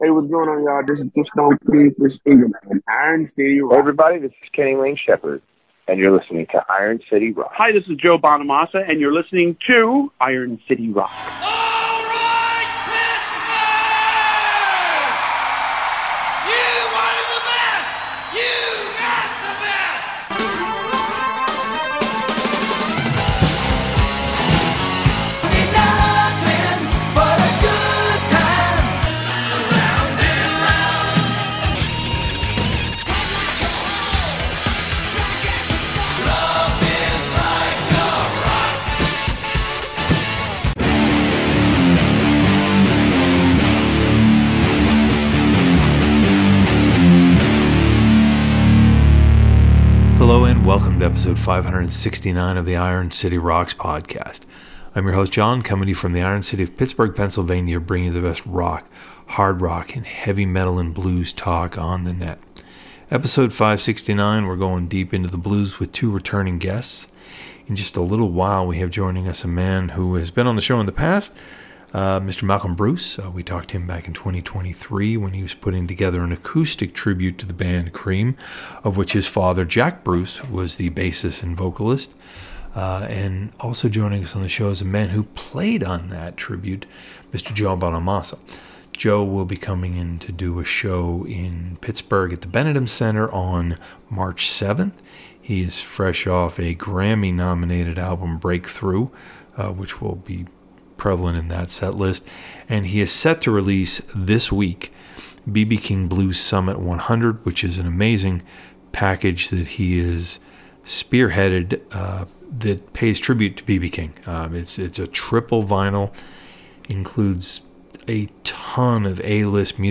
Joe Bonamassa joins us to talk about the B.B. King Blues Summit 100 and his Grammy nominated album Breakthrough.
In Episode 569 of the Iron City Rocks Podcast we are pleased to welcome returning guest Joe Bonamassa.